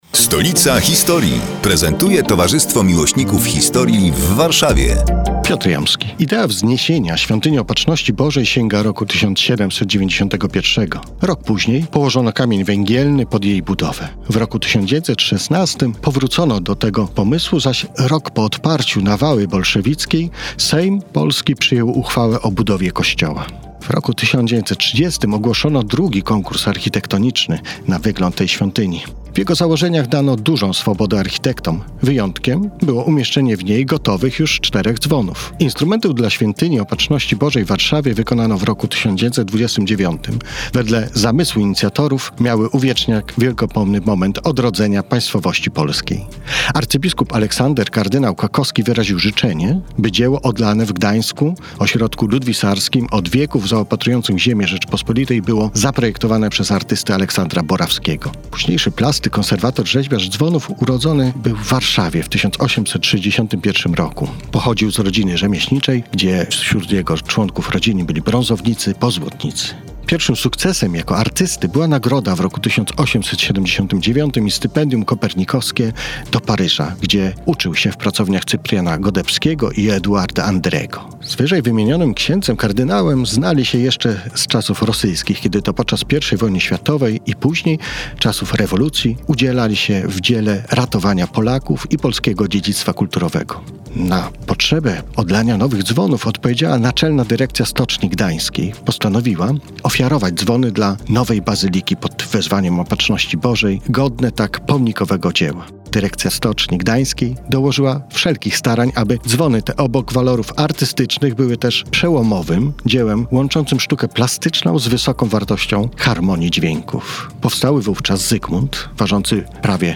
Kolejny felieton pod wspólną nazwą: Stolica historii.